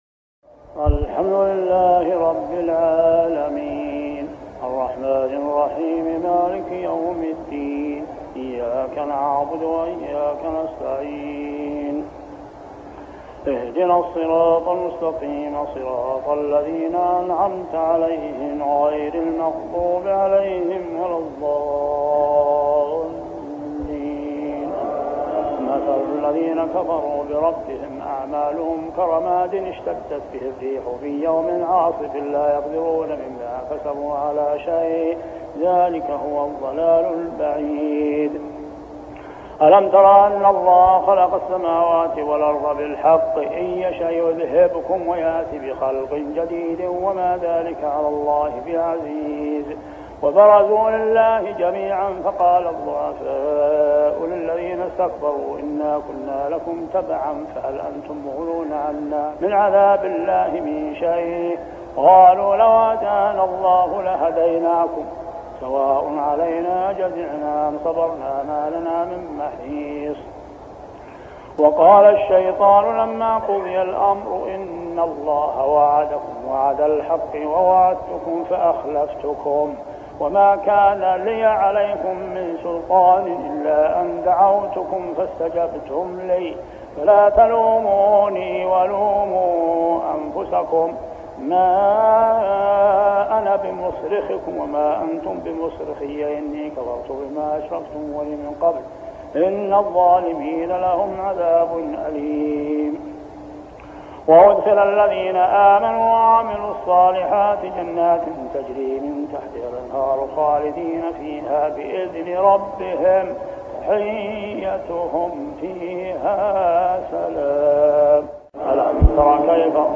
صلاة التراويح عام 1403هـ سورة إبراهيم 18-52 | Tarawih prayer Surah Ibrahim > تراويح الحرم المكي عام 1403 🕋 > التراويح - تلاوات الحرمين